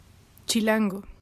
Chilango (pronounced [tʃiˈlaŋɡo]
Es-chilango.oga.mp3